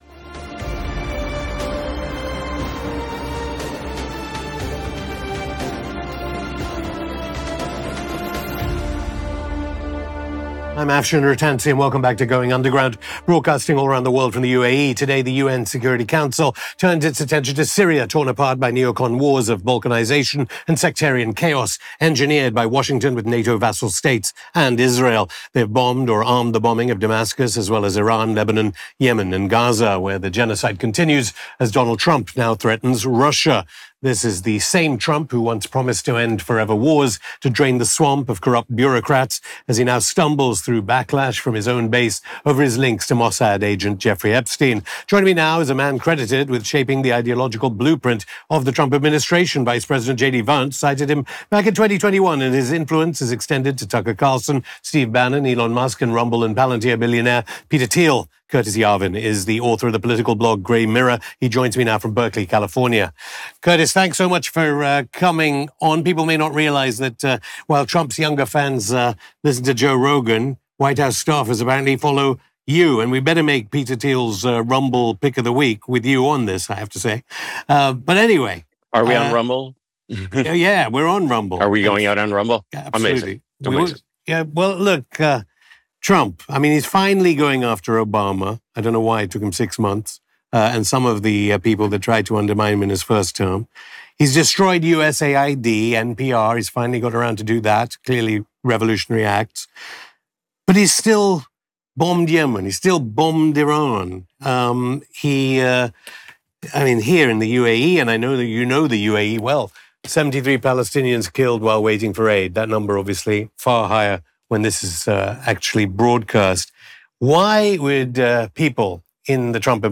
Curtis Yarvin: ‘Trump 47 is 10x More Powerful than Trump 45’ & the TOXIC US-Israel Relationship (Afshin Rattansi interviews Curtis Yarvin; 28 Jul 2025) | Padverb